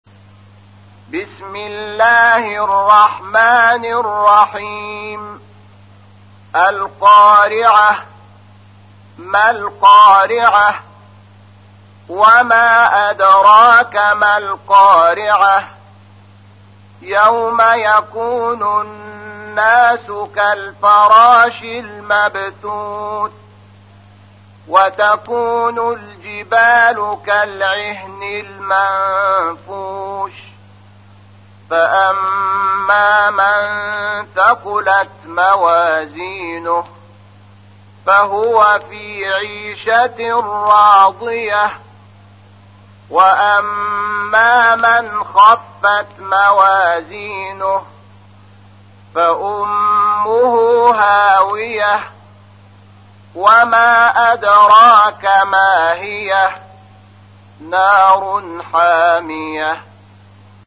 تحميل : 101. سورة القارعة / القارئ شحات محمد انور / القرآن الكريم / موقع يا حسين